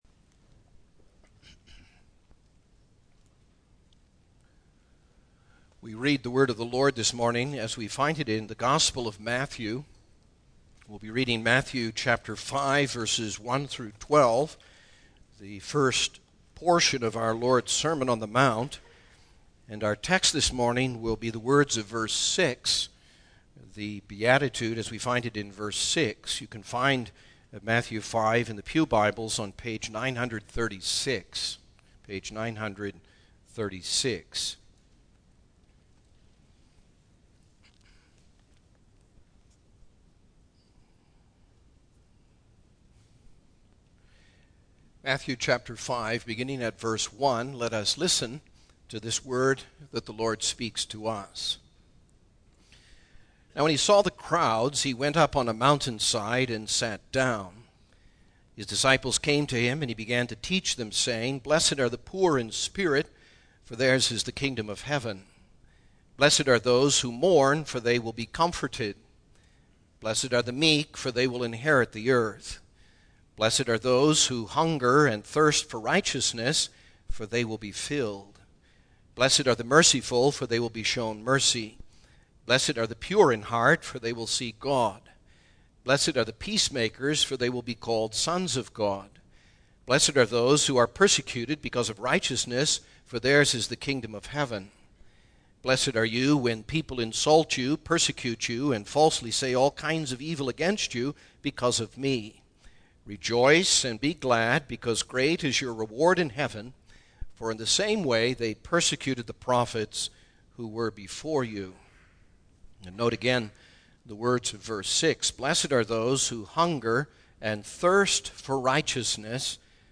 Single Sermons Passage: Matthew 5:1-12 %todo_render% « Blessed are the Merciful God’s Good Work in Us